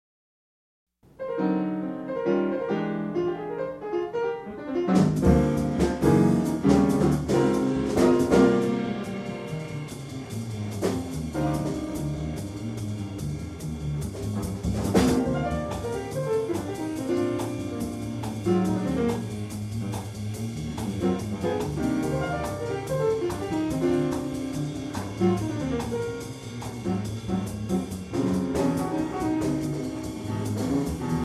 Recording: Feb.〜Sep.1999 　 at　 Holly's’ & Swing　Sing
息の合ったインプロビゼーションをお楽しみください。